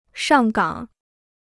上岗 (shàng gǎng) Free Chinese Dictionary
上岗.mp3